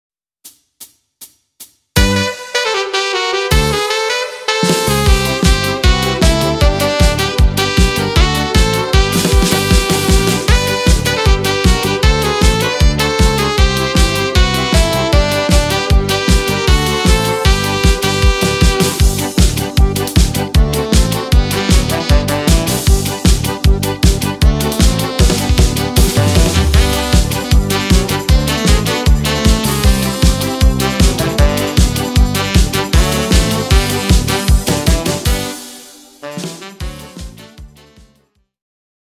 bardzo pozytywna piosenka